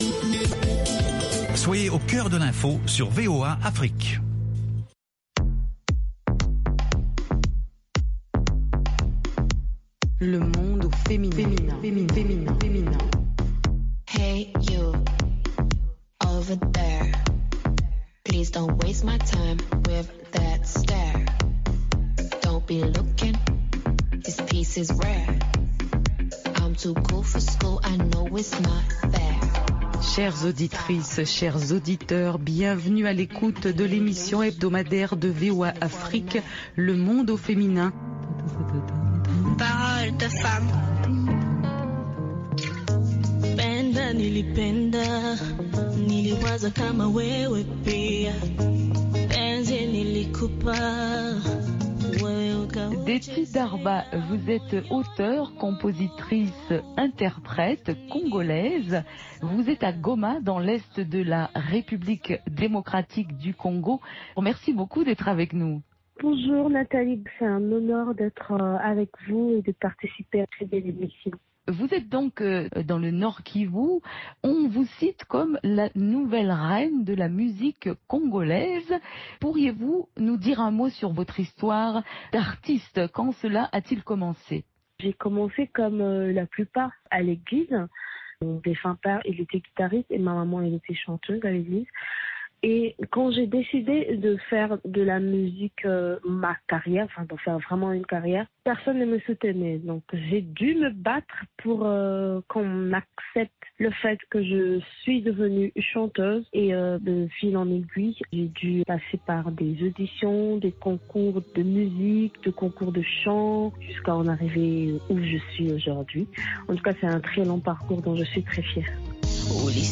Le Monde au Féminin: entretien musical